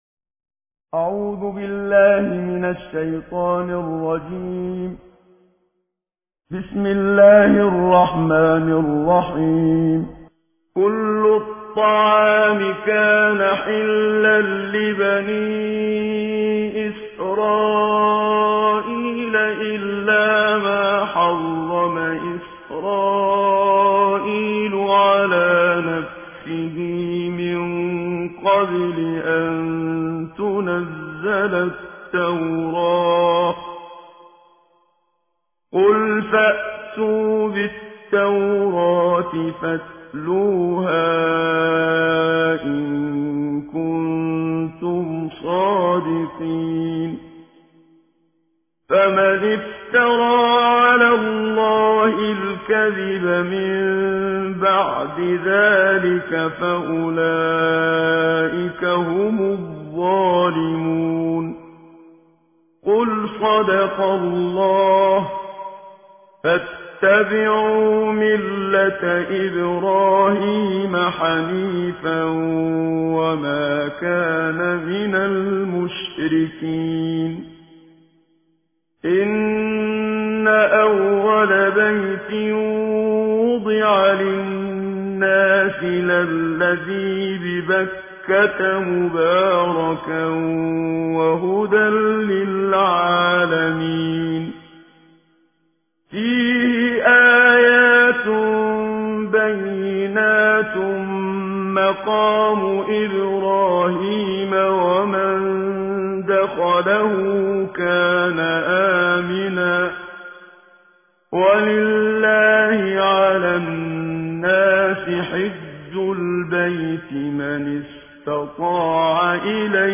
ترتیل جزء چهار قرآن کریم. با صدای استاد منشاوی